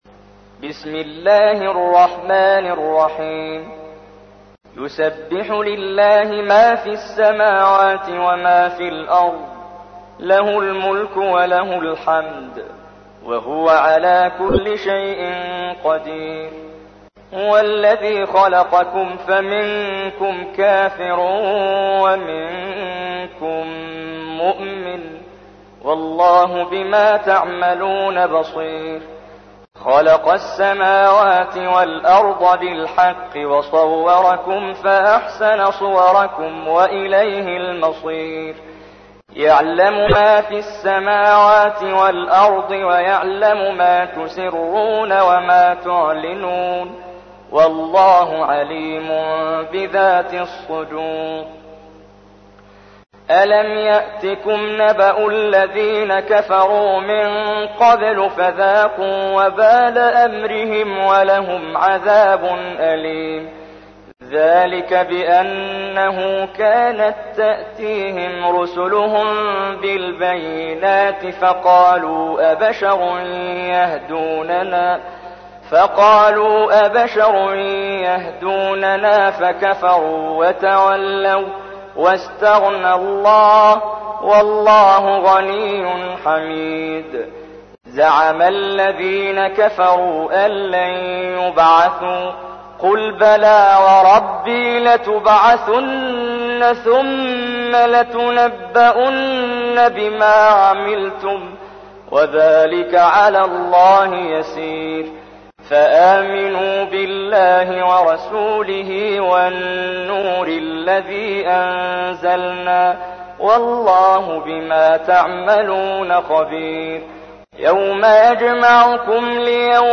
تحميل : 64. سورة التغابن / القارئ محمد جبريل / القرآن الكريم / موقع يا حسين